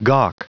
Prononciation du mot gawk en anglais (fichier audio)
Prononciation du mot : gawk